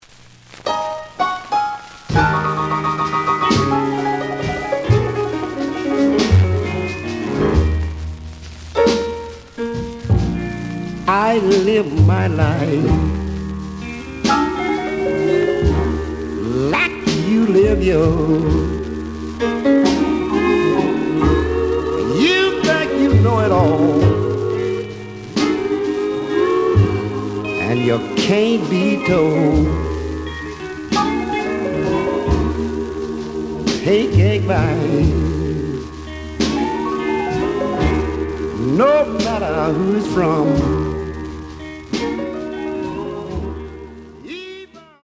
The audio sample is recorded from the actual item.
●Genre: Rhythm And Blues / Rock 'n' Roll
Looks good, but some noise on A side.)